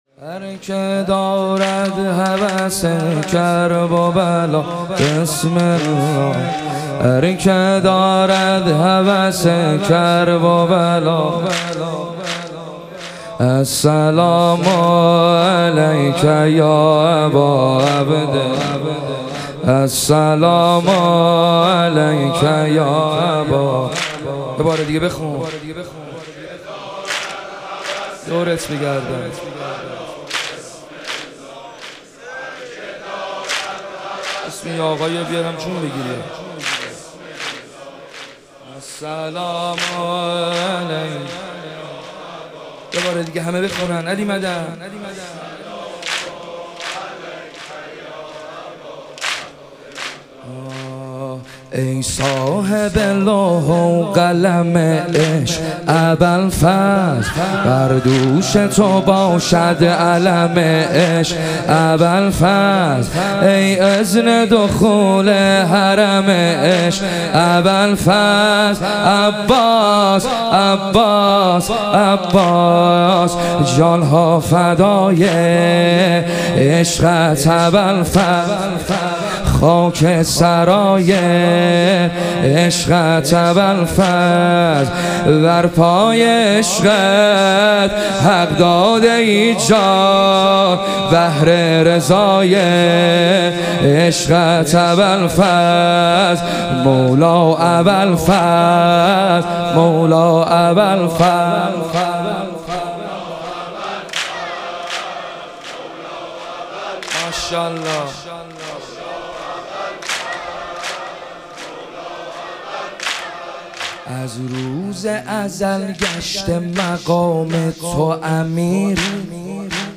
ایام فاطمیه دوم - واحد